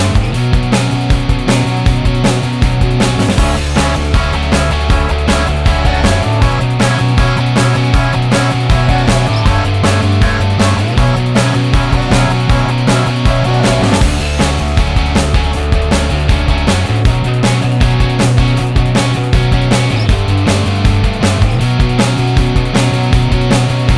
no Backing Vocals Rock 4:05 Buy £1.50